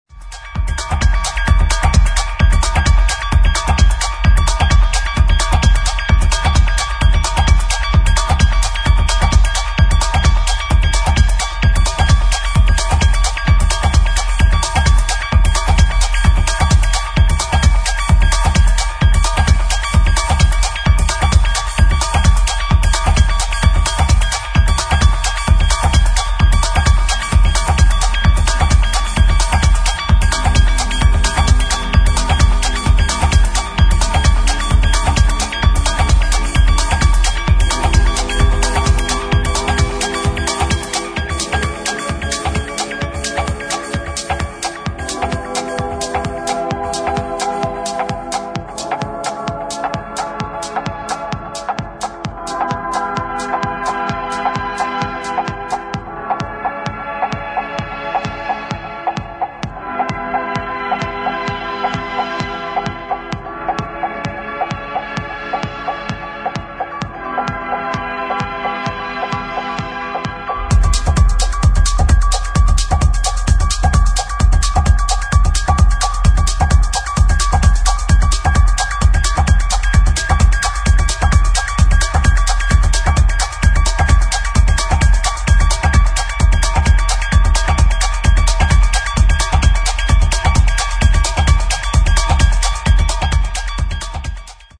[ TECHNO / BASS ]